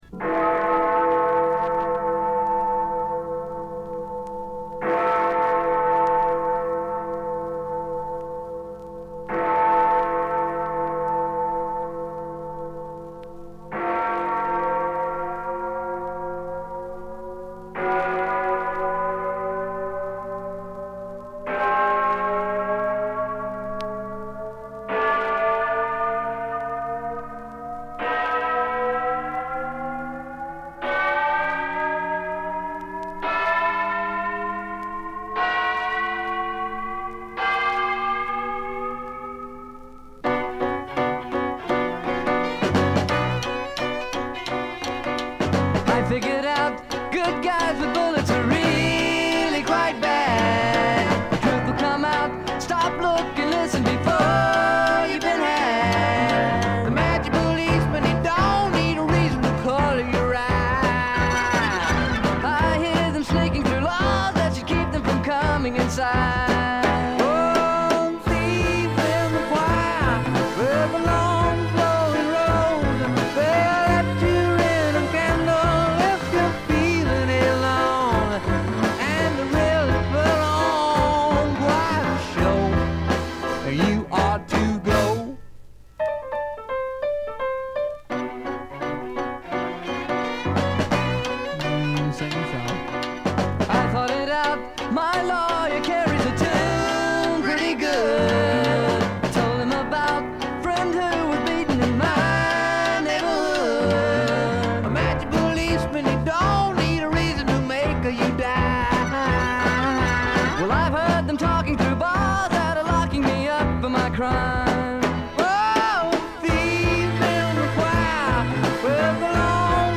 軽微なバックグラウンドノイズ、少しチリプチ。
60年代ポップ・サイケな色彩でいろどられたサージェント・ペパーズな名作！！
試聴曲は現品からの取り込み音源です。